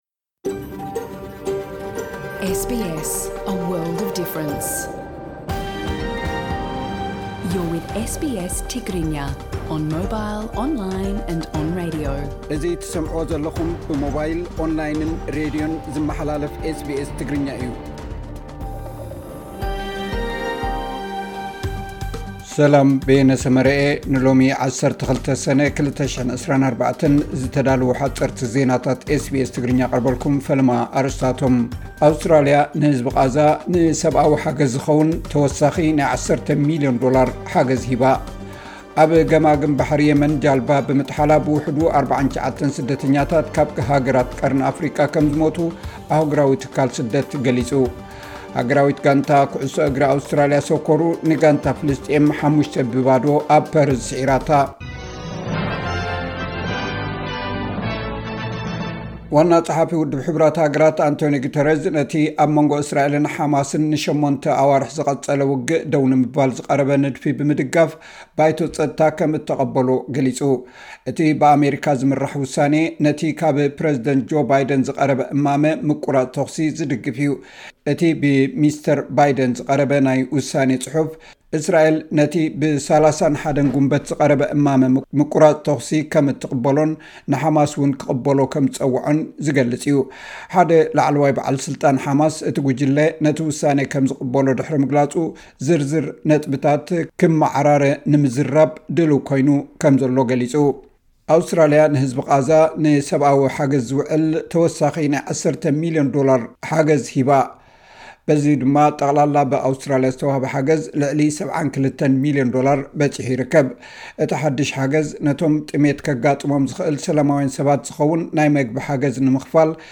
ሓጸርቲ ዜናታት ኤስ ቢ ኤስ ትግርኛ (12 ሰነ 2024)